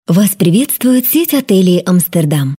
004wЗаботливый женский голос